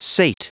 Prononciation du mot sate en anglais (fichier audio)
Prononciation du mot : sate